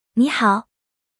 Edge TTS 试听台
在 Cloudflare Worker 上快速试听、筛选、收藏 Edge Read Aloud voices。